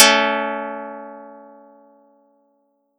Audacity_pluck_5_14.wav